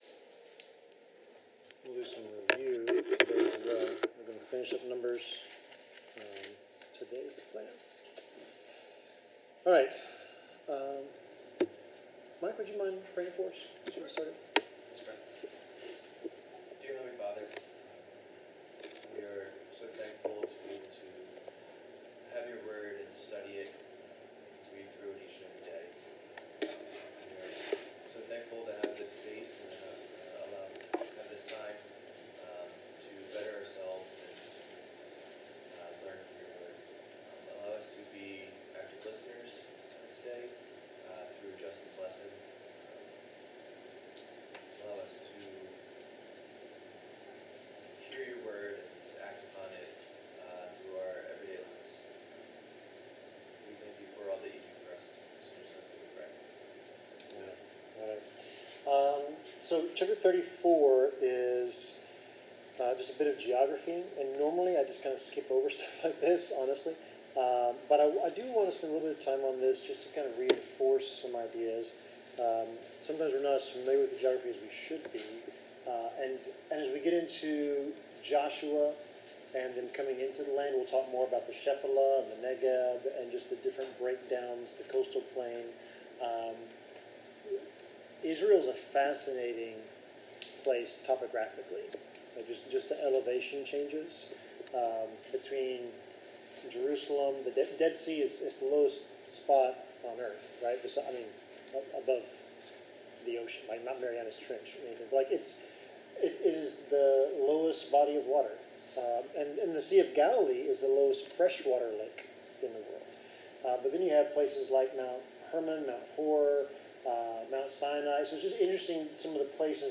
Bible Class: Numbers 33-36